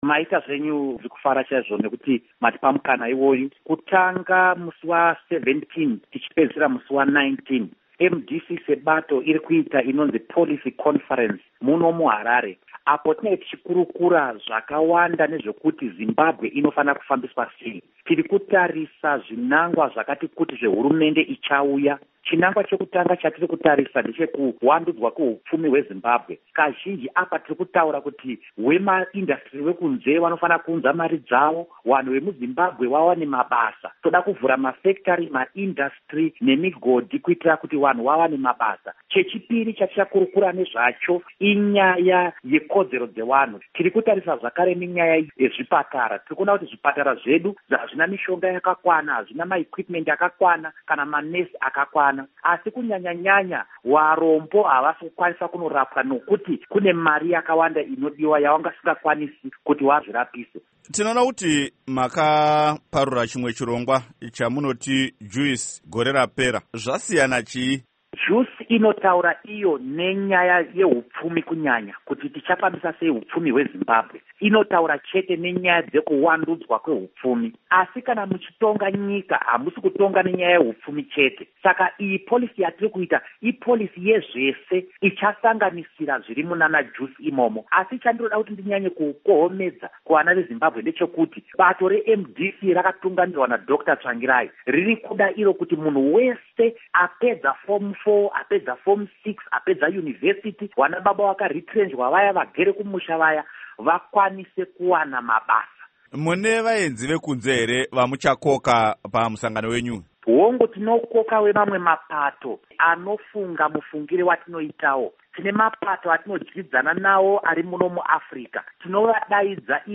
Hurukuro naVaPsychology Maziwisa